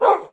bark1.mp3